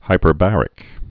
(hīpər-bărĭk)